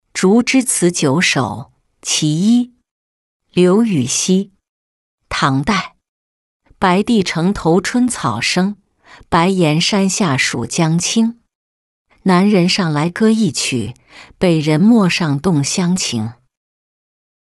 竹枝词九首·其一-音频朗读